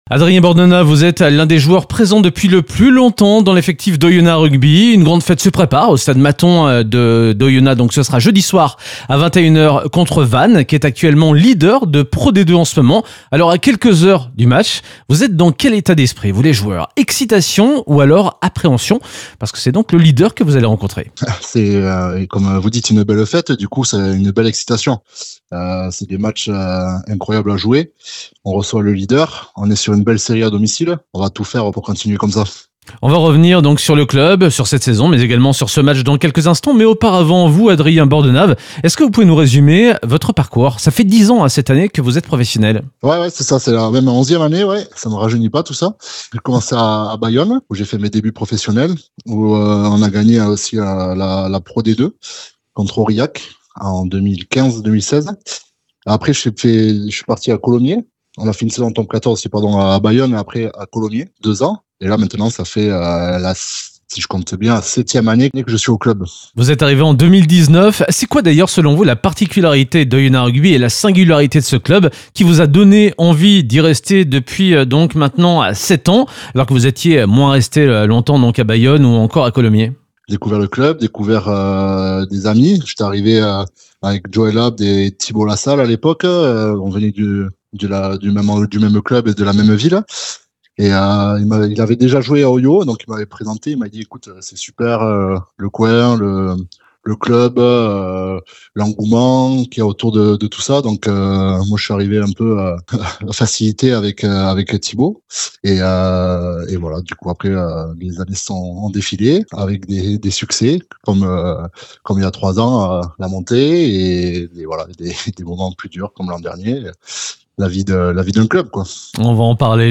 Interview de la Rédaction